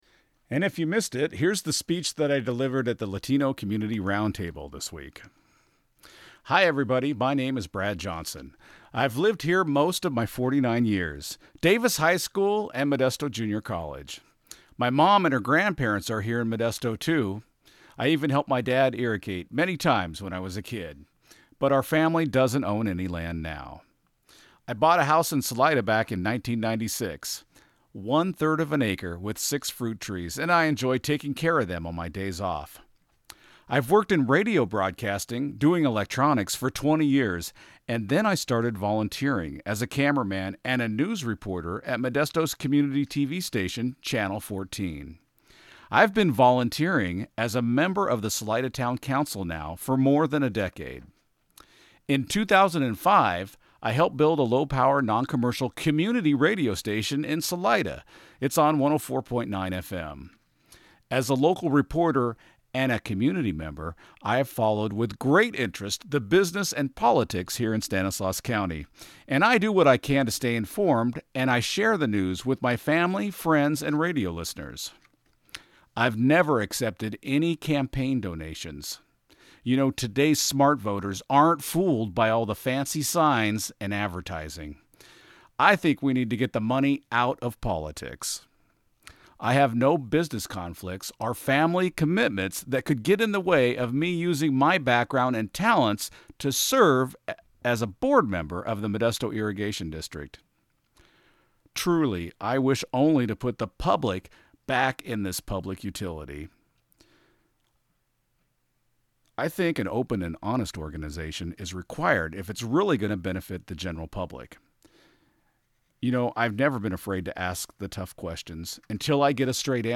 speech
LCR-speech-2min-.mp3